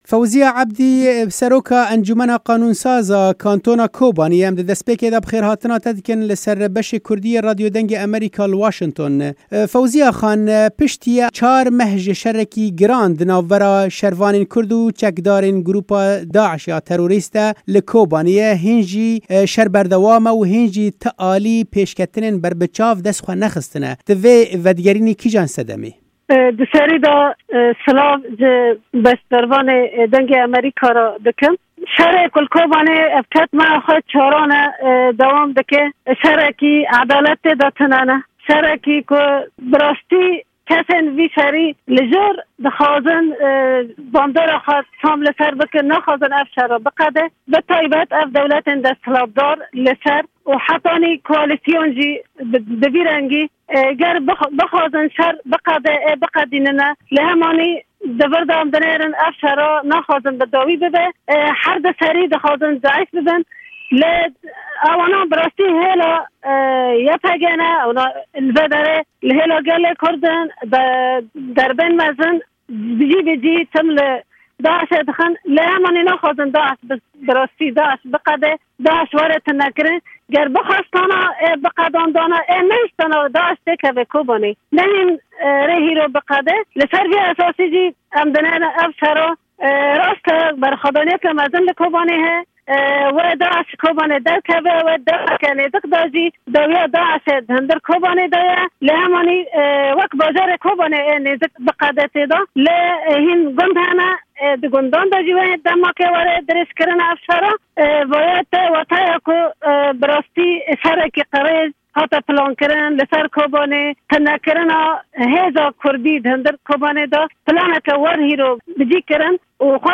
Seroka encûmena Qanûnsaz a Kantona Kobaniyê Fewziya Ebdî, ji Dengê Amerîka re ev babet șîrove kir û ew wek pilanekê ji aliyê hêzên mezin yên beșdar ve anî ziman.